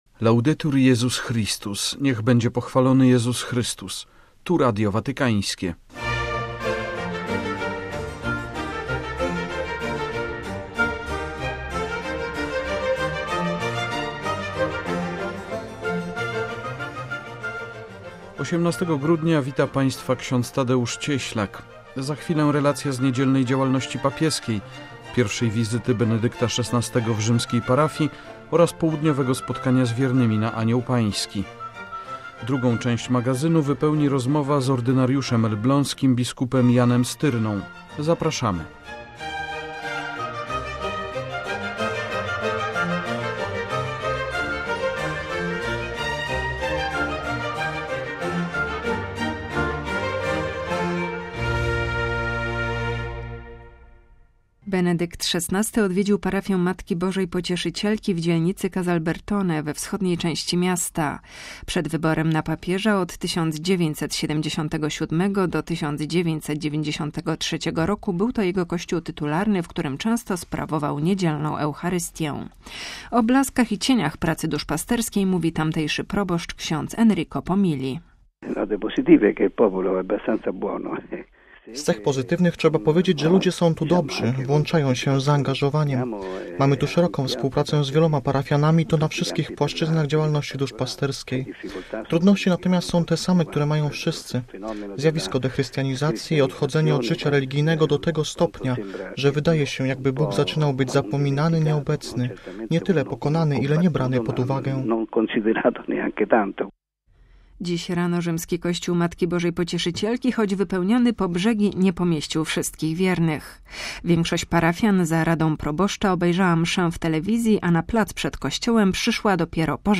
- relacja z niedzielnej działalności papieskiej – pierwszej wizyty Benedykta XVI w rzymskiej parafii oraz południowego spotkania z wiernymi na Anioł Pański; - gościem magazynu jest ordynariusz elbląski, bp Jan Styrna.